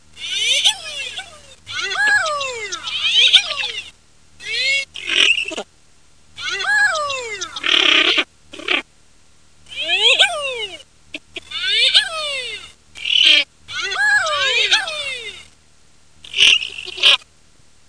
Звуки манка
Утка нырок красноголовый